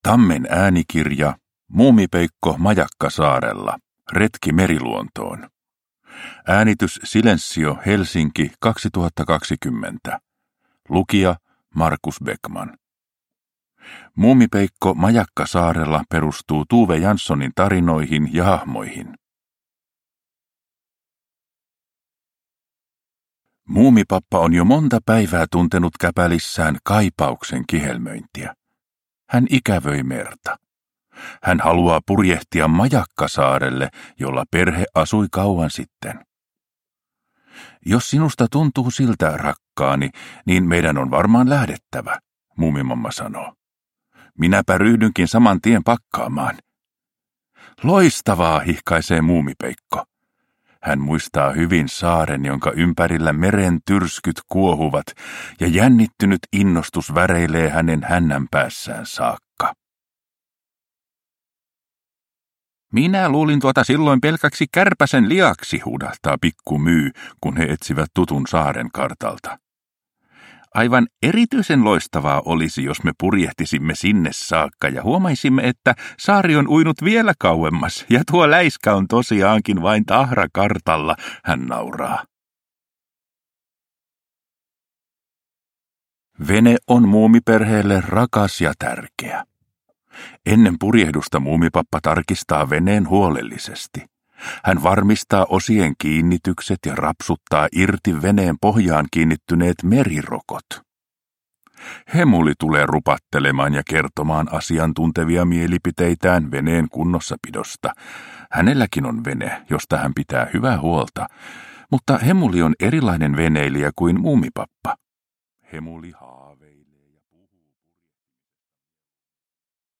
Muumipeikko majakkasaarella – Ljudbok – Laddas ner